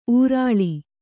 ♪ ūrāḷi